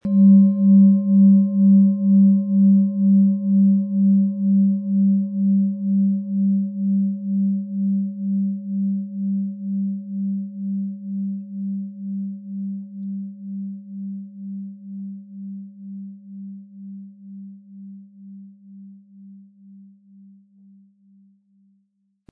Planetenschale® Im Vertrauen sein & Erdung mit Merkur & Tageston, Ø 16,6 cm, 500-600 Gramm inkl. Klöppel
Sie sehen eine Planetenklangschale Merkur, die in alter Tradition aus Bronze von Hand getrieben worden ist.
• Tiefster Ton: Tageston
PlanetentöneMerkur & Tageston
MaterialBronze